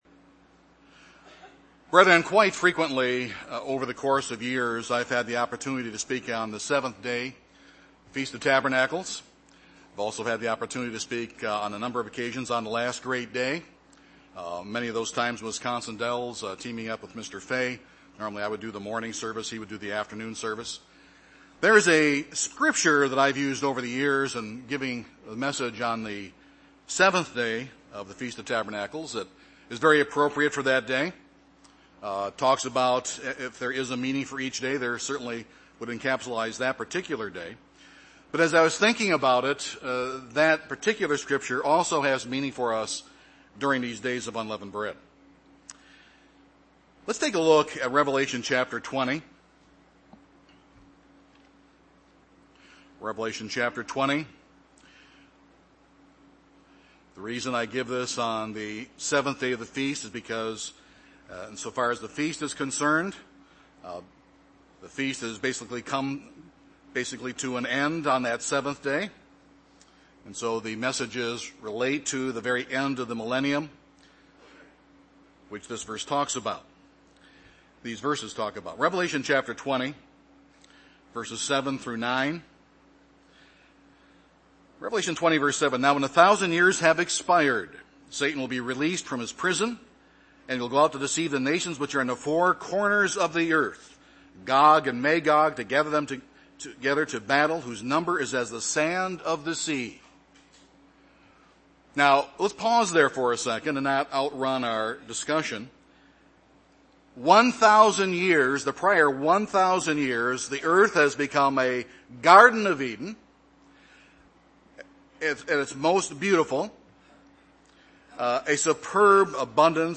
This sermon was given at the Oconomowoc, Wisconsin 2016 Feast site.